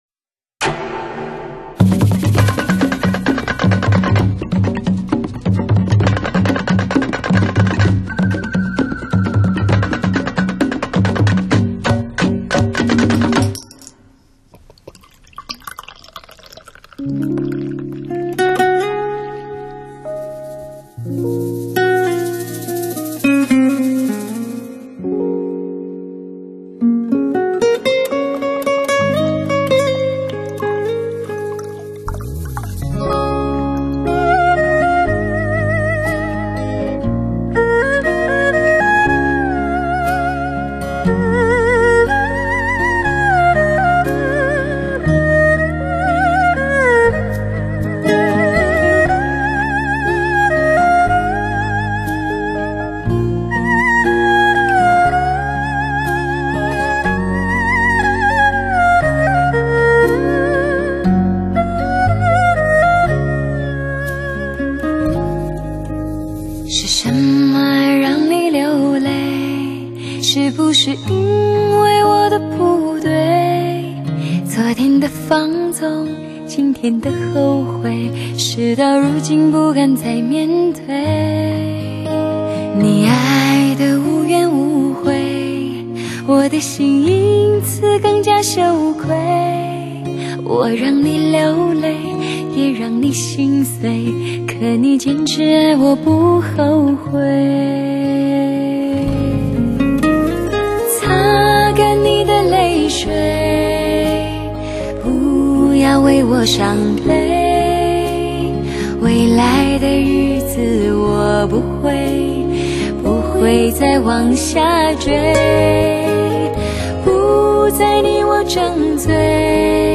绝妙的女声磁场
首创国际SRS+WIZOR全方位360°环绕Hi-Fi AUTO SOUND 专业天碟